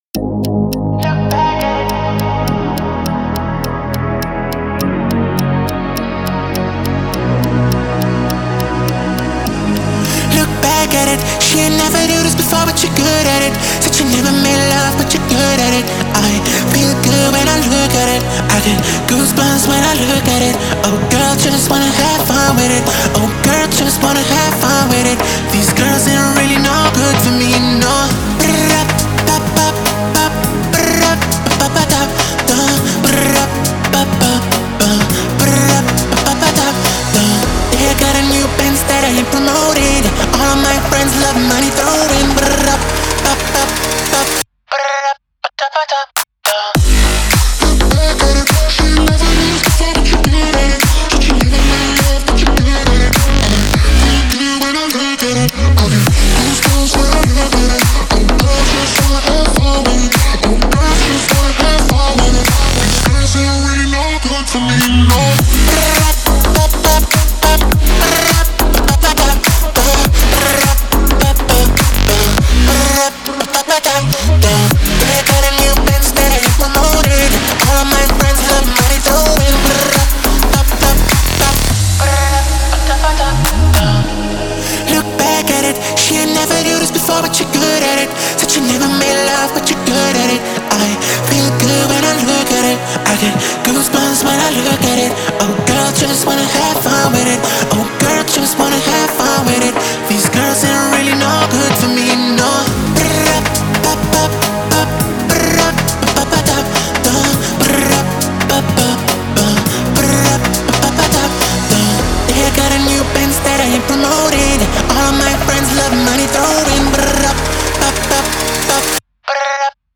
это динамичная композиция в жанре электронной музыки